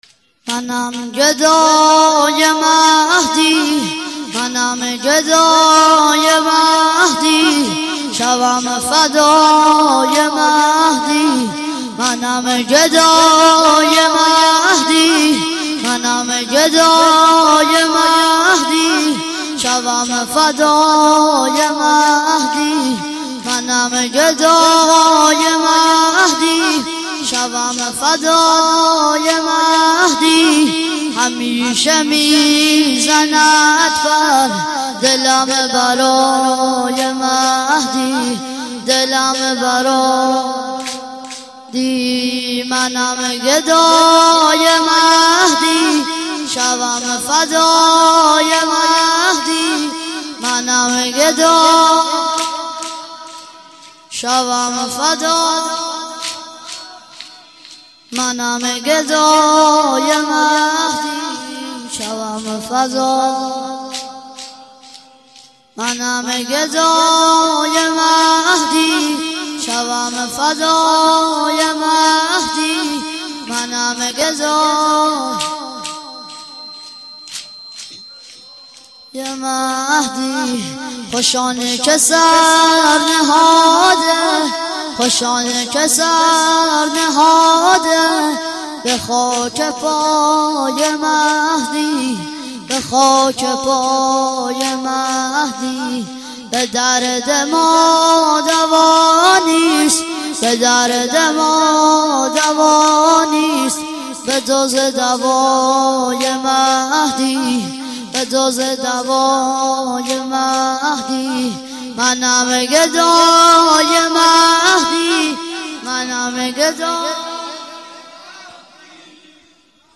چهار ضرب - منم گدای مهدی